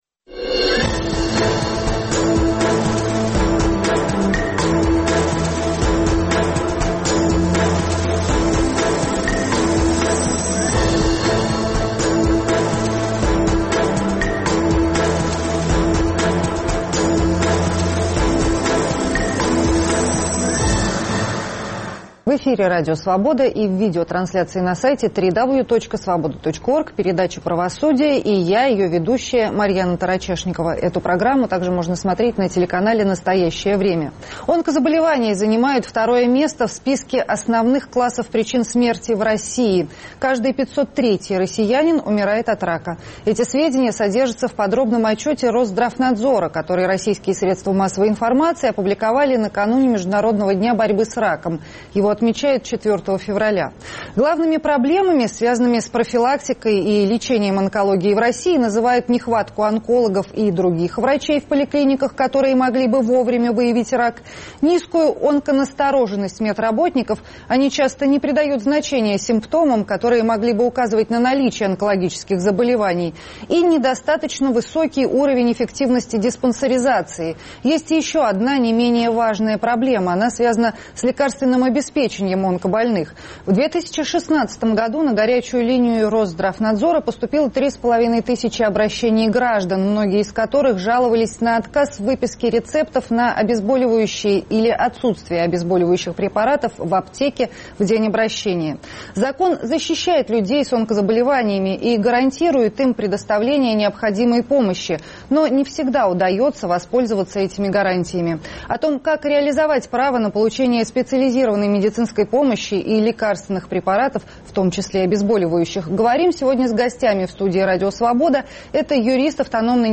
О том, как людям с онкологическими заболеваниями реализовать право на получение специализированной и бесплатной медицинской помощи и лекарственных препаратов (в том числе, обезболивающих), говорим с гостями в студии Радио Свобода